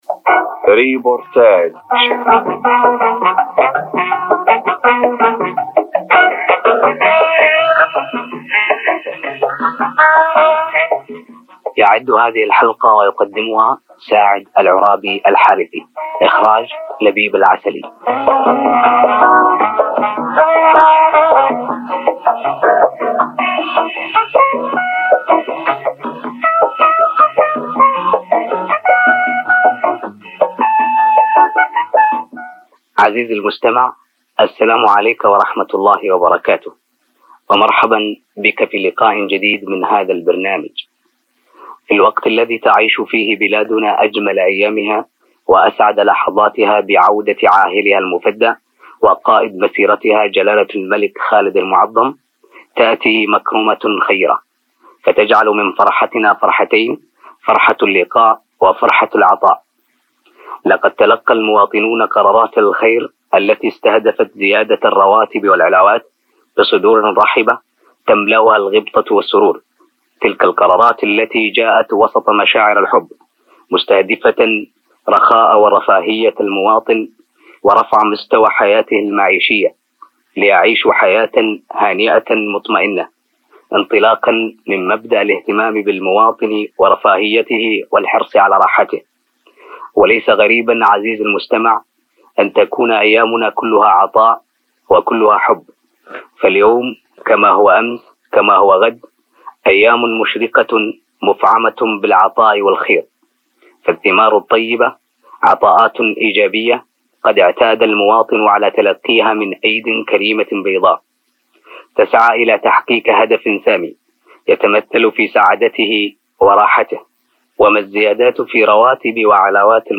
ريبورتاج- مقابلته مع تركي السديري
ريبورتاج-مقابلته-مع-تركي-السديري.mp3